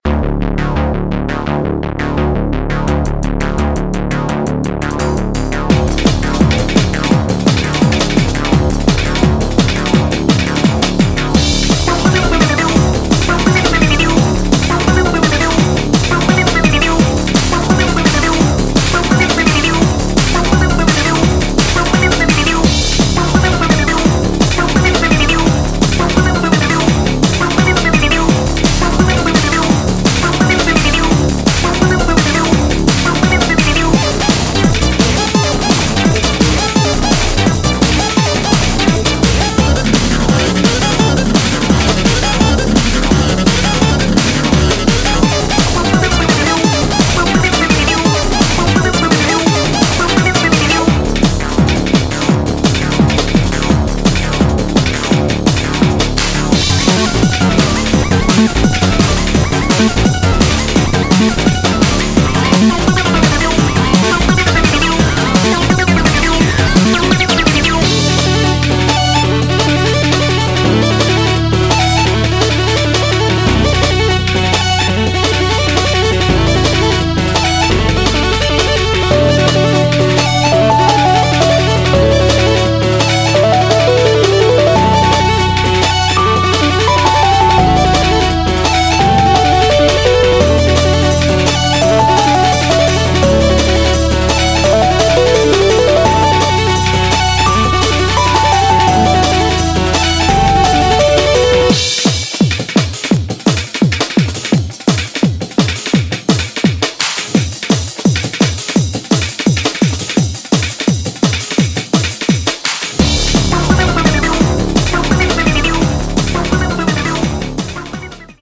BPM170
Audio QualityPerfect (High Quality)